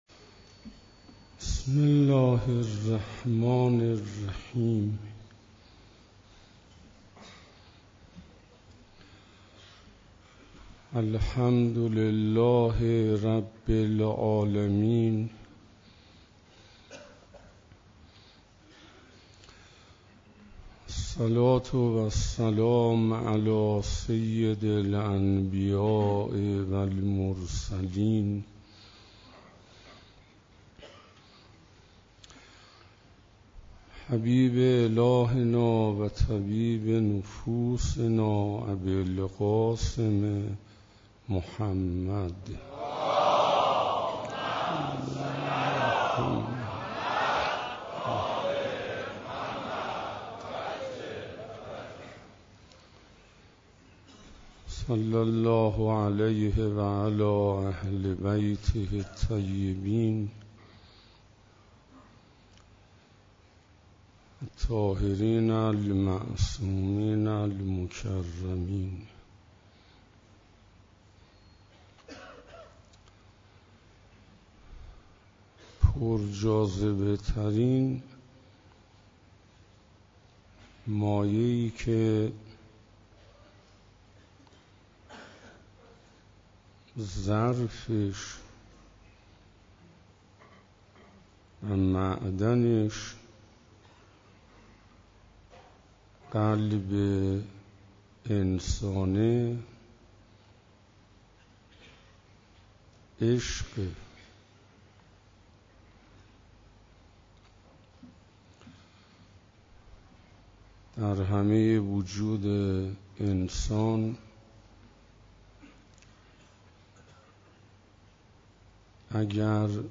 شب 20 رمضان97 - حسینیه همدانی ها